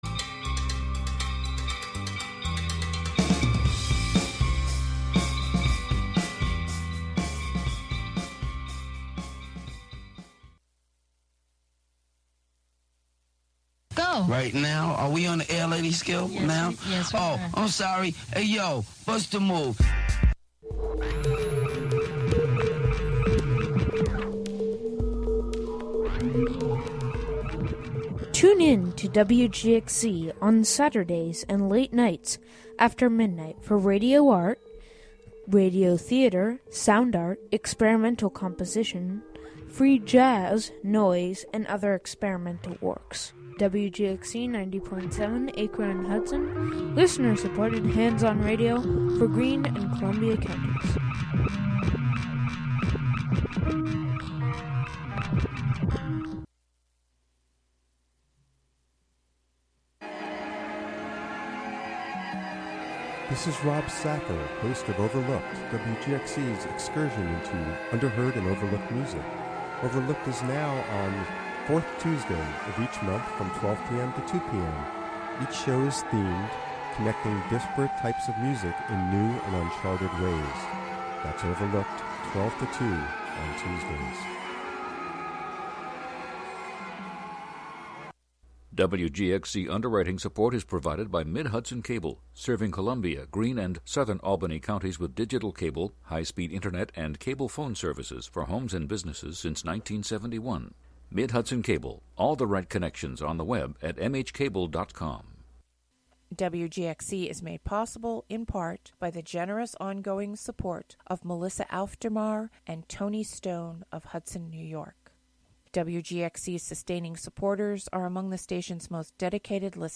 "All Together Now!" is a daily news show covering radio news, and news about the Hudson Valley.
"All Together Now!" features local and regional news, weather updates, feature segments, and newsmaker interviews. Hear frequent reports from WOOC-LP, WGXC's partner station in Troy, New York.